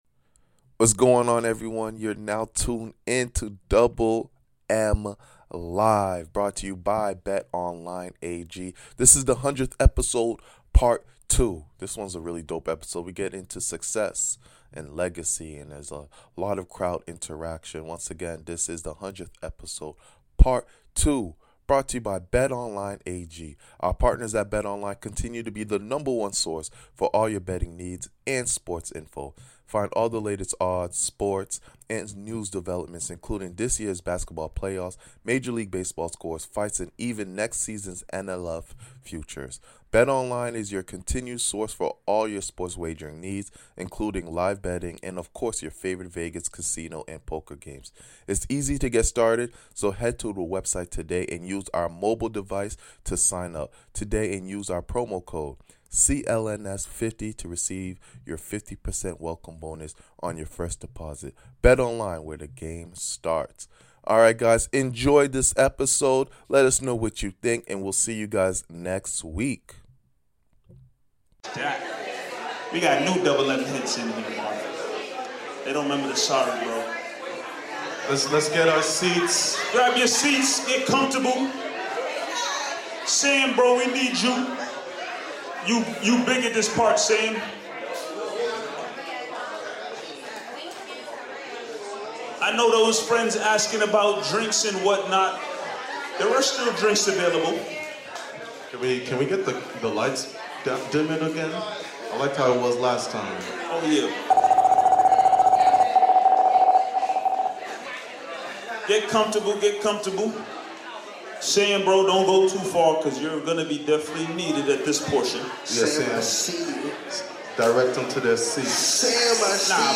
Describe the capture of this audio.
We talk about Success and Legacy during this half of Double M and get a lot of audience interaction.